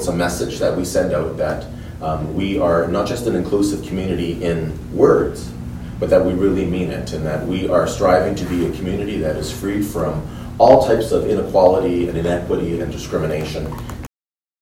During opening remarks at the art gallery, Mayor Panciuk called the flag raising an important symbol.